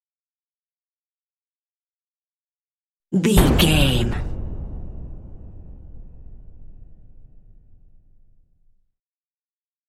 Dramatic Hit Trailer
Sound Effects
In-crescendo
Atonal
heavy
intense
dark
aggressive
hits